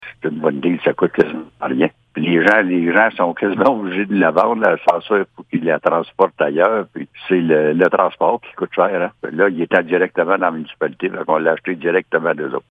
Le maire de Messines, Ronald Cross, précise pourquoi il s’agit d’une bonne affaire pour la Municipalité dans un période de précarité économique :